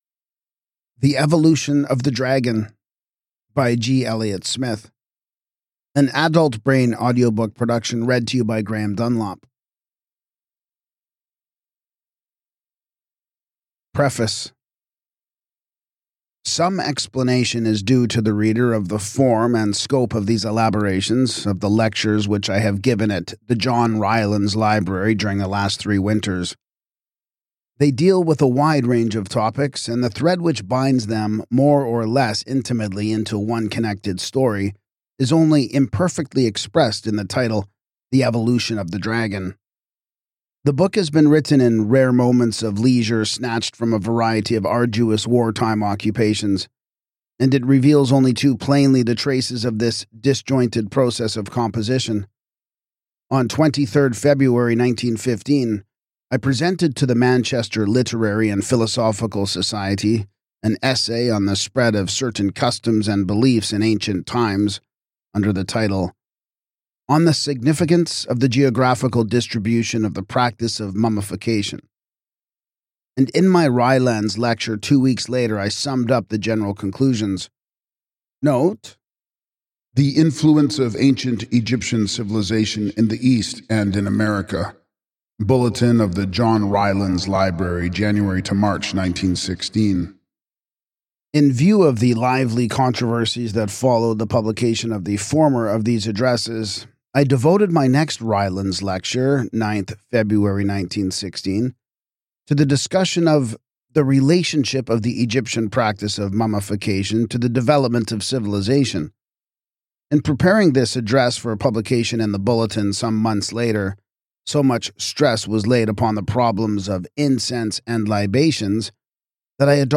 Ideal for scholars, esoteric enthusiasts, and mythology lovers, this audiobook offers a deep, intellectual journey into humanity’s ancient imagination and shared symbols.